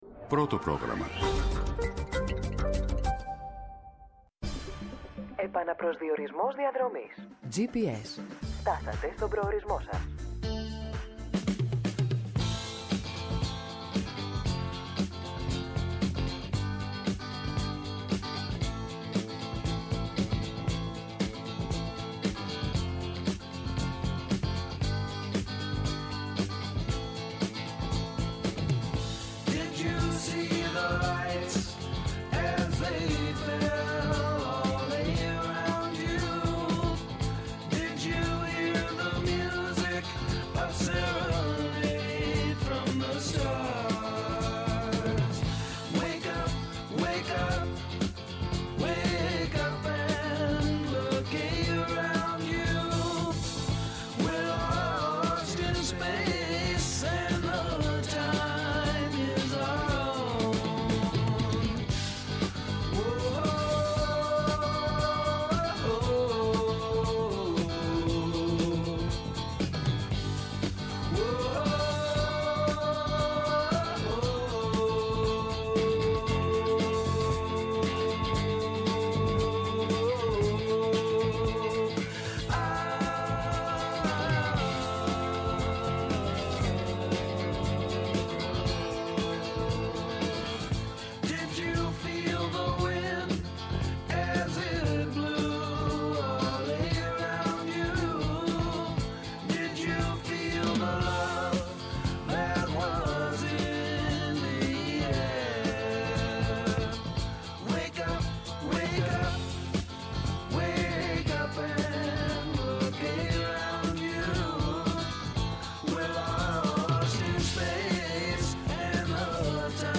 Η Σοφία Βούλτεψη, υφυπουργός Μετανάστευσης και Ασύλου.